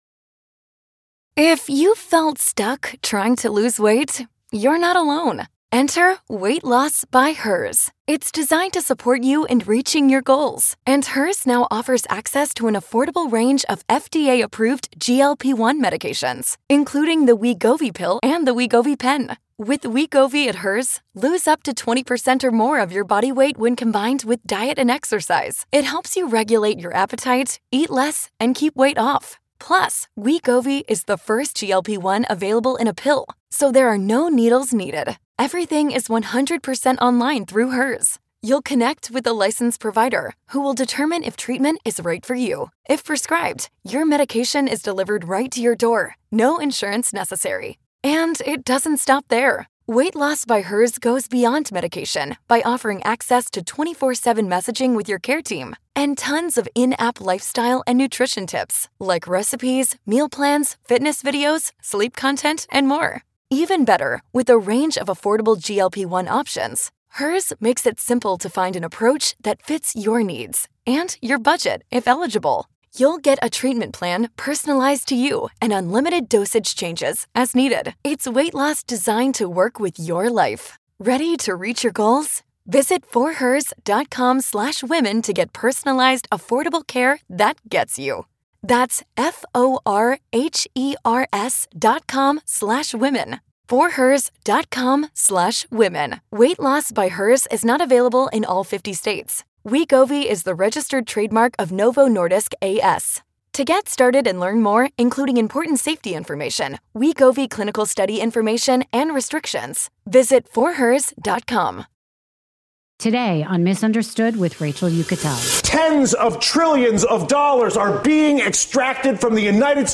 In this raw and thought-provoking conversation, Dylan opens up about why he left media, what he sees in America’s political future, and why the fight for reform is personal. He also shares what it’s like to reinvent himself while staying true to the convictions that made him one of the most outspoken voices in journalism.